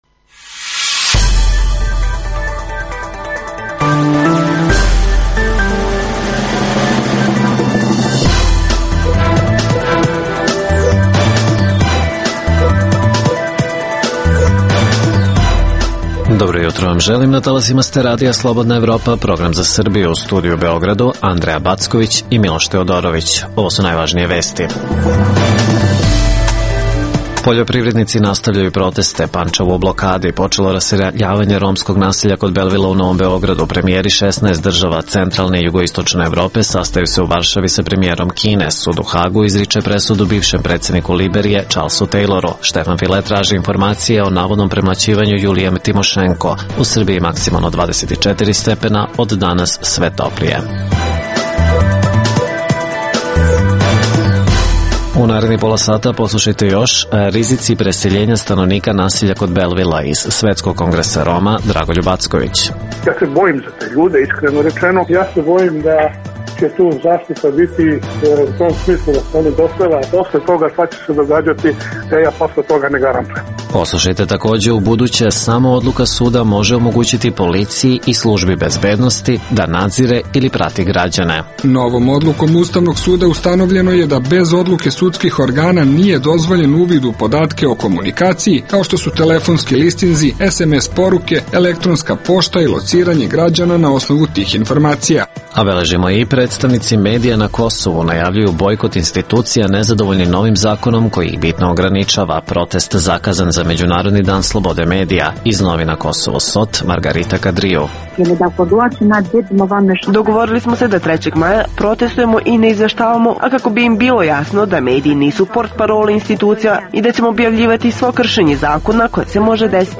Izveštavamo sa lica mesta. - Poljoprivrednjici u Vojvodini drugi dan blokiraju Pančevo. - Studenti u Beogradu najavljuju štrajk glađu.